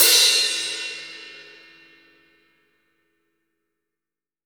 OZ20CRASH1-S.WAV